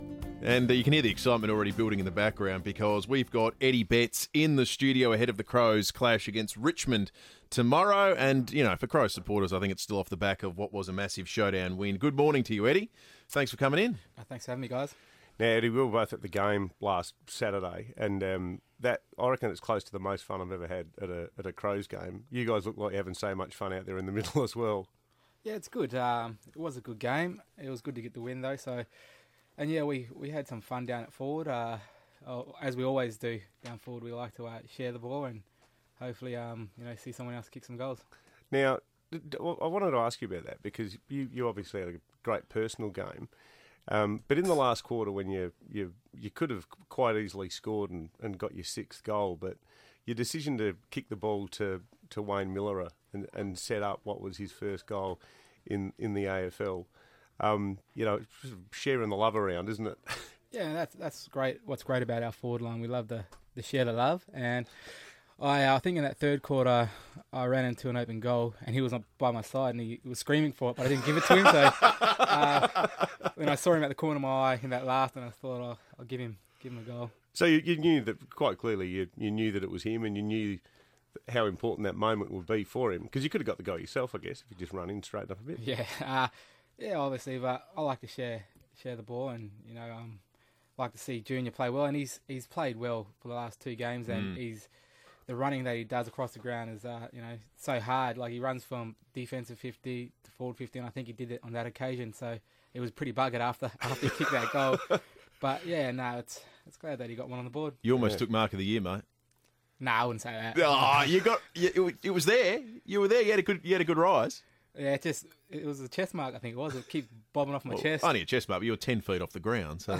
Crows livewire Eddie Betts joined the FIVEaa Breakfast team ahead of Adelaide's Round Three clash with the Tigers